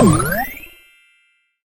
pickup_powerup.ogg